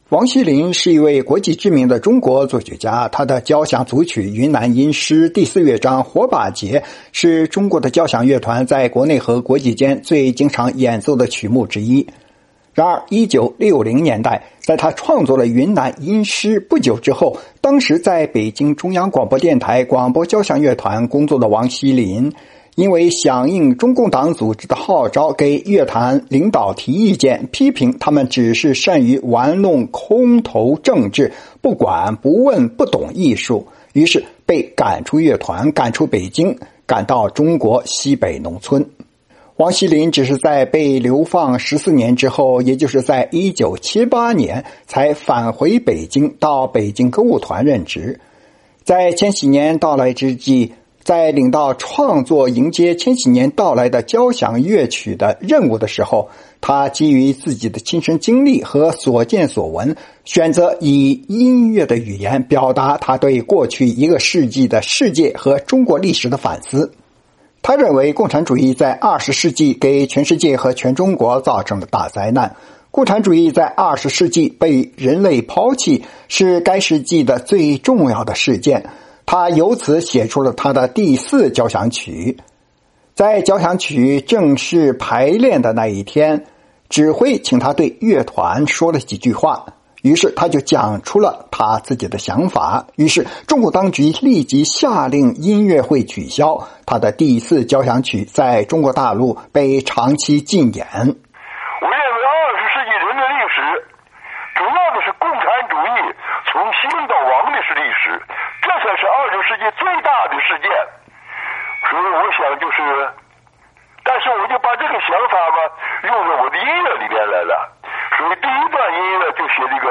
专访作曲家王西麟(1)： 谈用音乐表现中国人的苦难